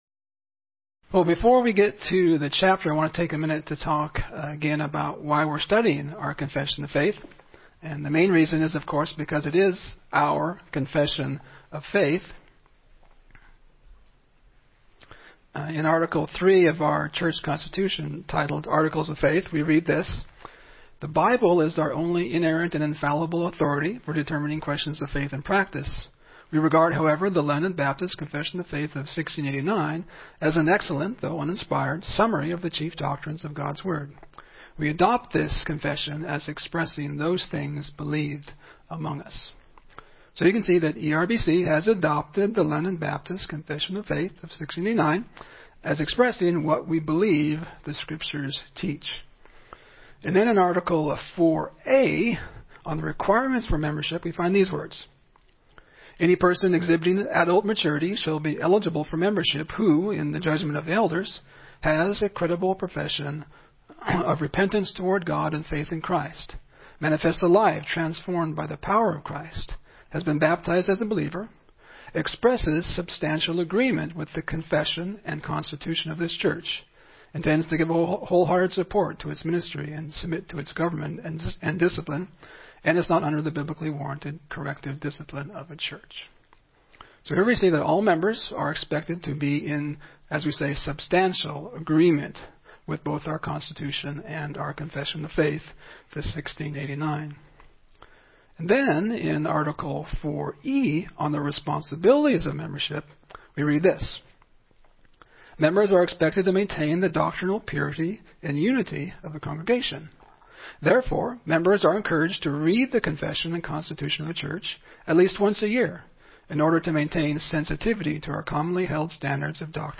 Series: 1689 Confession Essentials Service Type: Sunday School